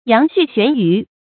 羊續懸魚 注音： ㄧㄤˊ ㄒㄩˋ ㄒㄨㄢˊ ㄧㄩˊ 讀音讀法： 意思解釋： 羊續，漢時官吏。